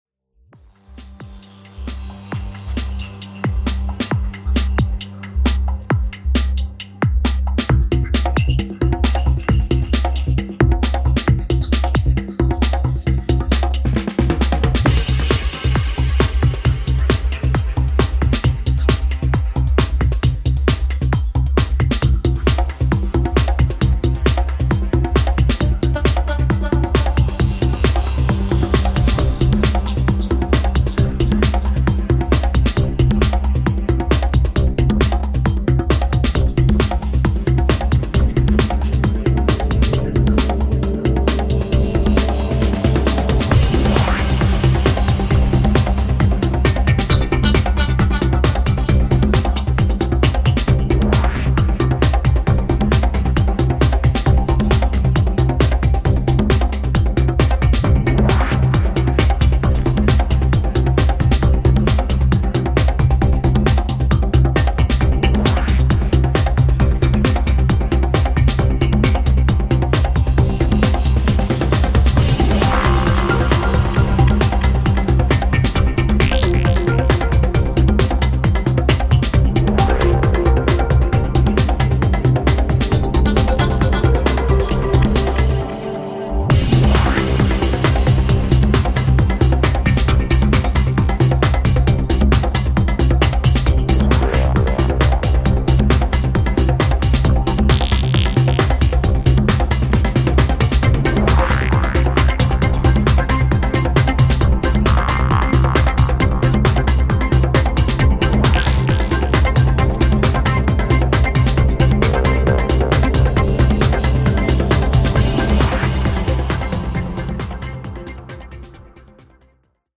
modernized progressive house